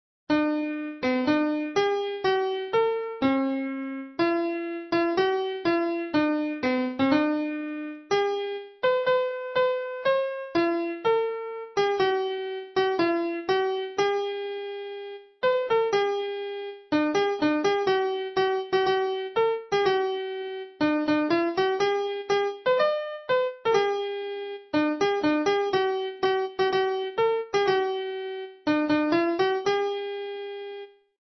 Больше ничего не помню, только мелодию — бодрую и красивую.